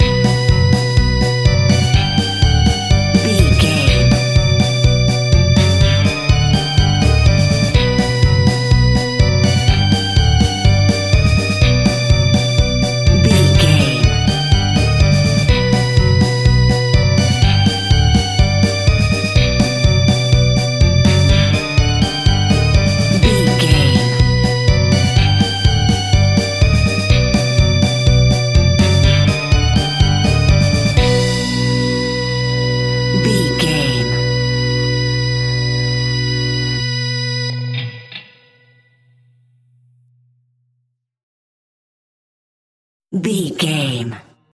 Diminished
ominous
dark
haunting
eerie
electric organ
piano
bass guitar
drums
spooky
horror music